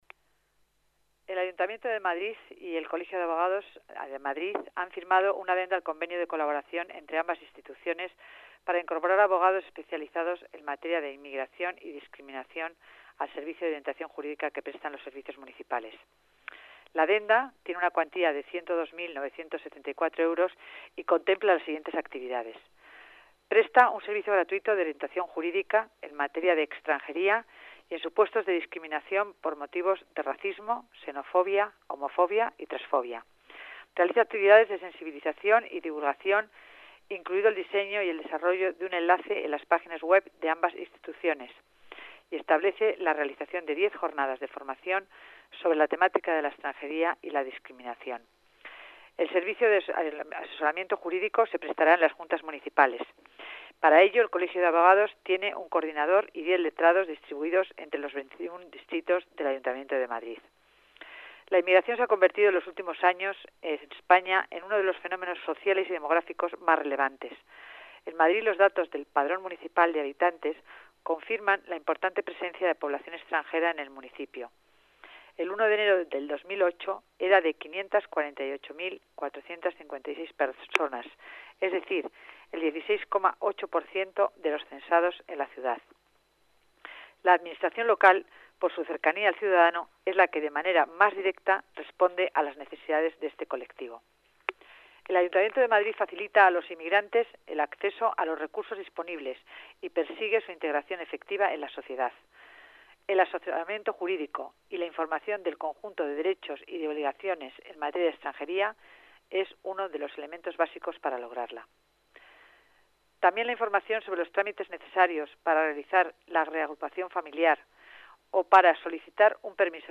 Nueva ventana:Declaraciones de la delegada de Familia y Servicios Sociales, Concepción Dancausa